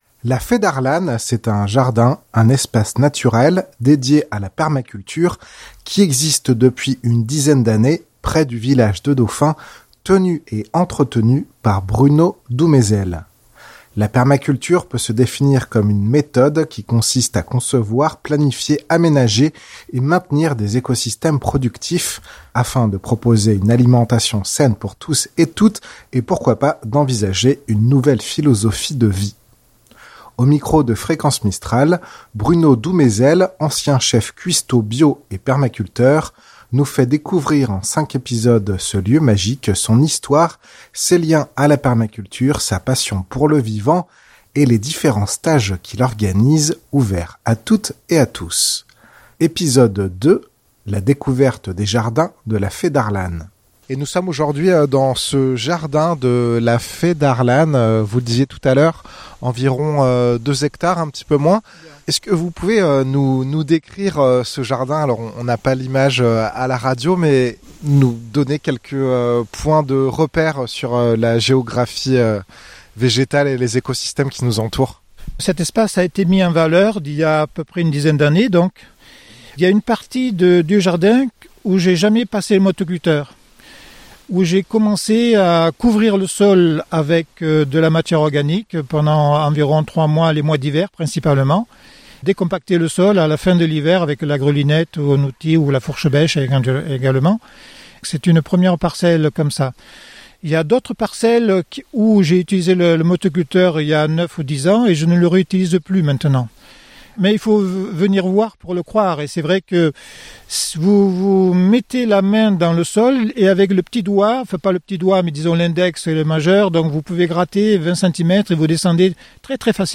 Épisode 2, la découverte des jardins de la Fée d’Arlane Reportage